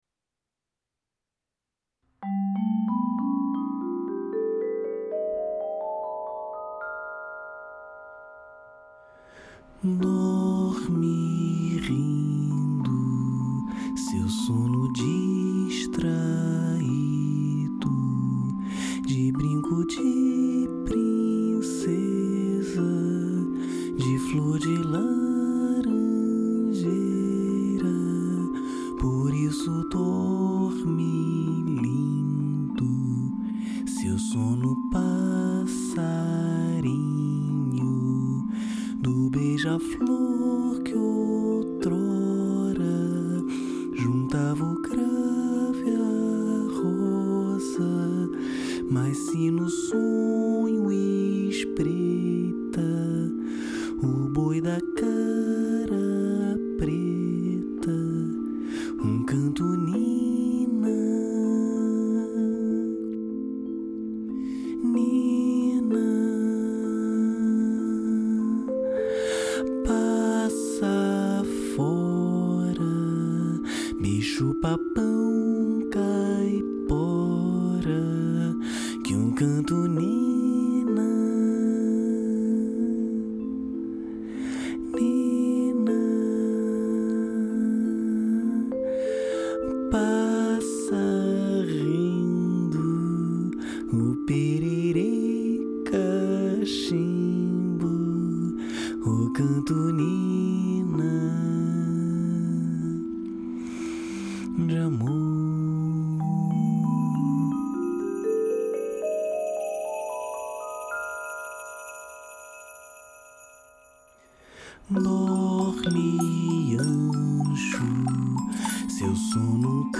Vibrafone Paz Reflexiva